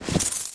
snd_ui_buy.wav